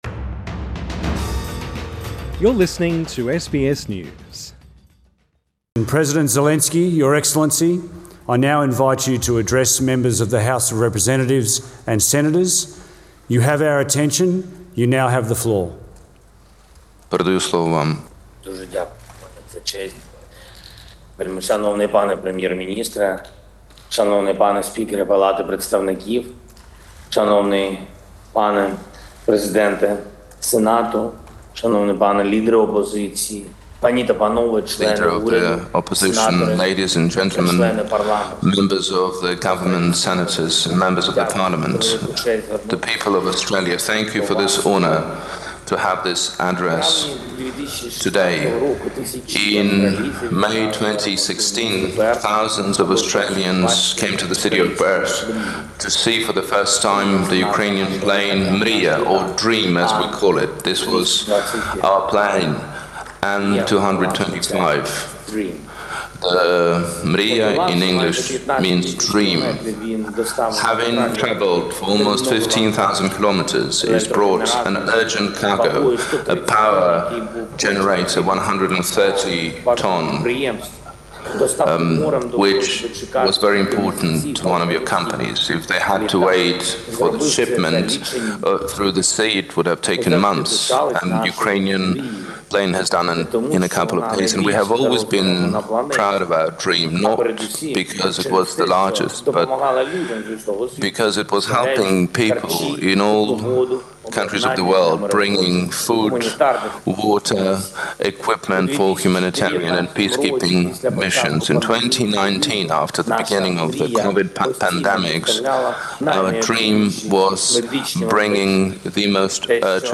Australian members of Parliament applaud as Ukrainian President Volodymyr Zelenskyy addresses the House of Representatives via a video link at Parliament House in Canberra.